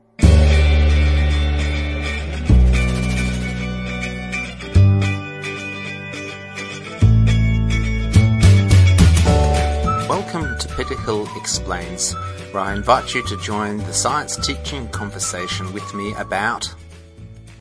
Trialing the new shot gun microphone MP4 recording MP3 recording